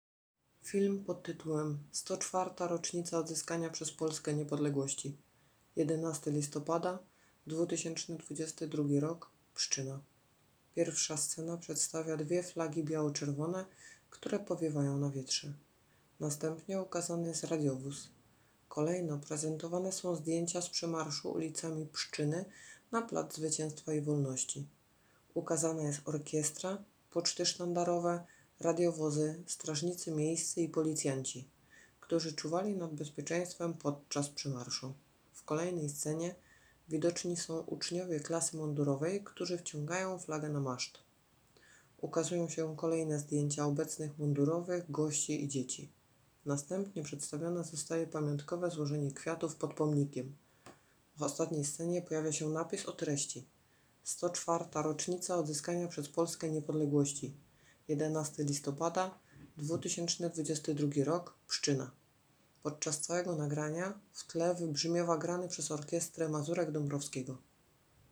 Obchody 104. rocznicy odzyskania przez Polskę Niepodległości w Pszczynie
Drugą część wszyscy zgromadzeni w ramach ogólnopolskiej akcji „Niepodległa do hymnu” rozpoczęli od wspólnego odśpiewania Mazurka Dąbrowskiego. Kolejno przemawiała starosta pszczyńska – Barbara Bandoła i zastępca burmistrza Pszczyny - Magdalena Czarnecka.